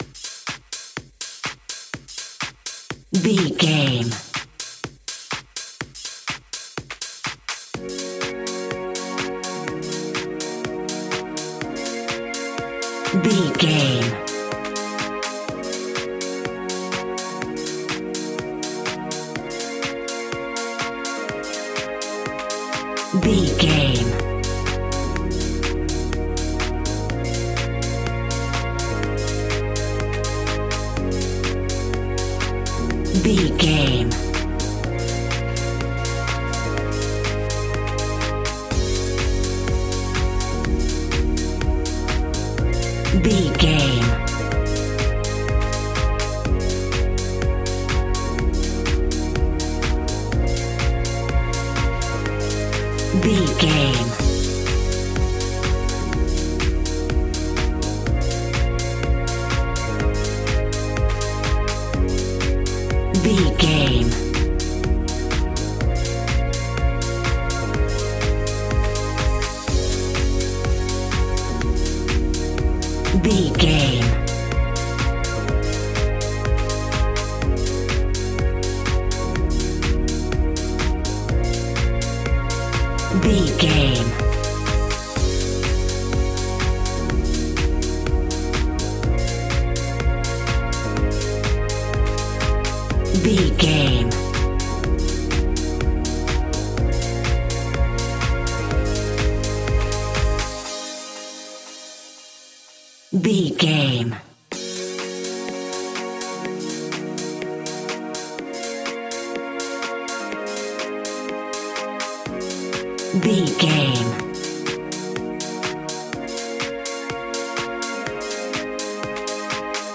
Aeolian/Minor
B♭
groovy
hypnotic
uplifting
drum machine
synthesiser
house
Funk
electro house
synth leads
synth bass